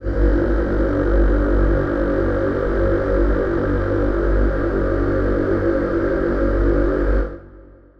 Choir Piano
G1.wav